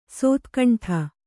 ♪ sōtkaṇṭha